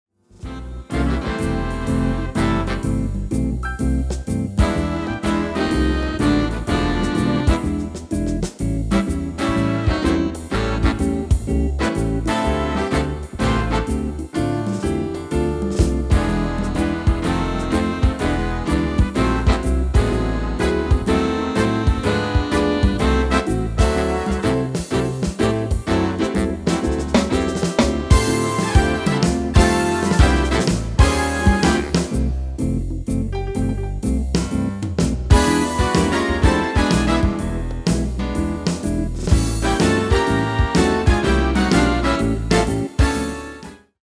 Genre: Jazz / Big Band
You are buying a GM-Only midi-arrangement inclusive: